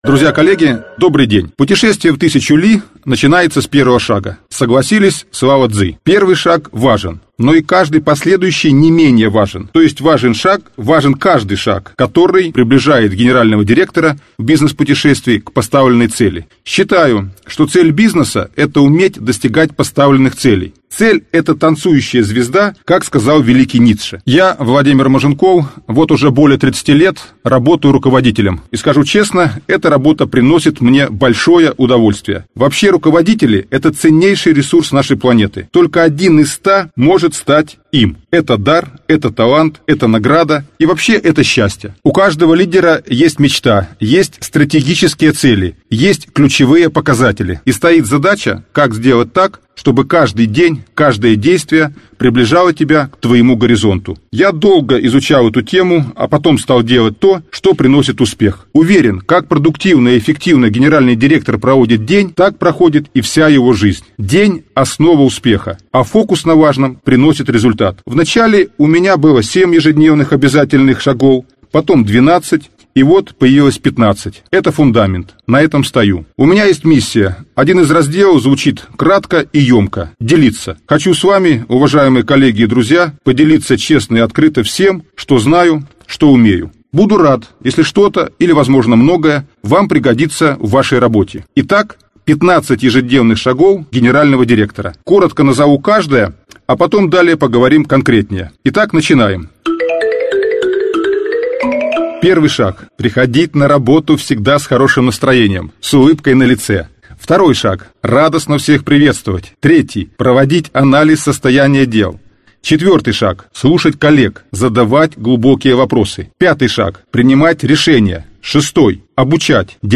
Аудиокнига 15 ежедневных шагов руководителя | Библиотека аудиокниг